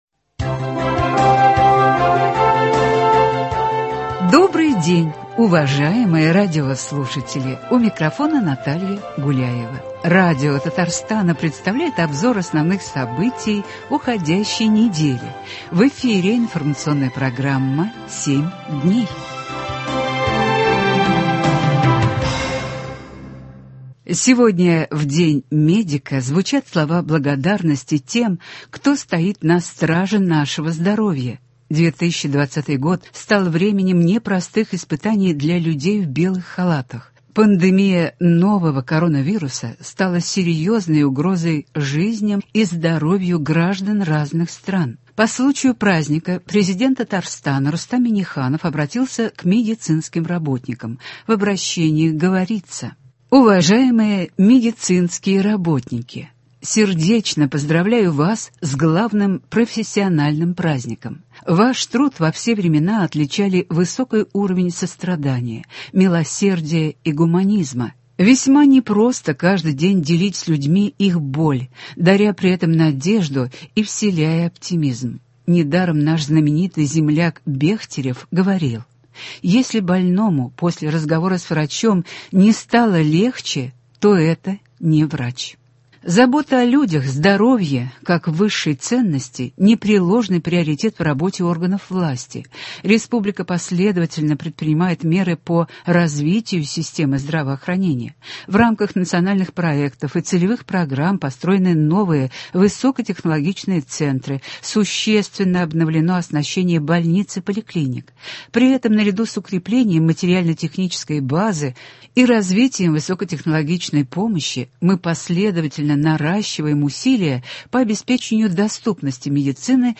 Обзор событий.